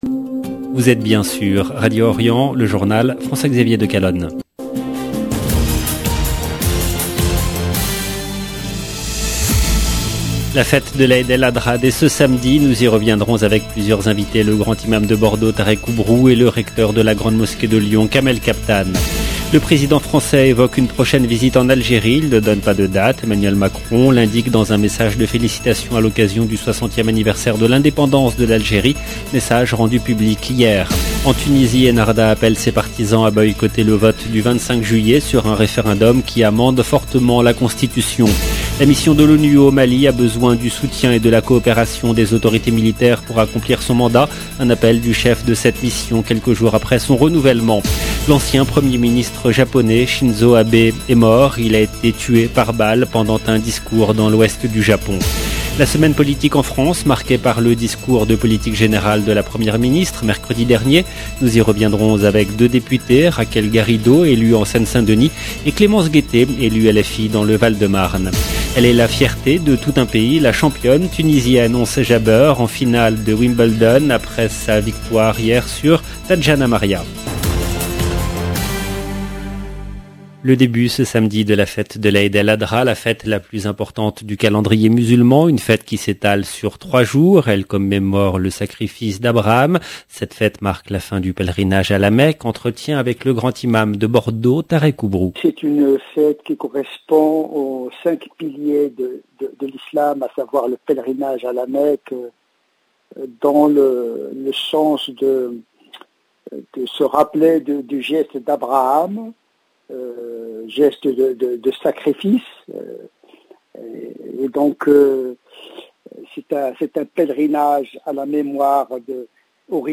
LE JOURNAL DU SOIR EN LANGUE FRANCAISE DU 8/07/22 LB JOURNAL EN LANGUE FRANÇAISE